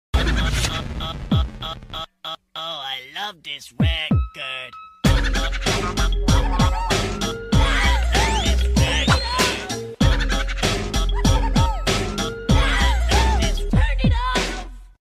Dream accidentally k!lled George but still laughed happily :))))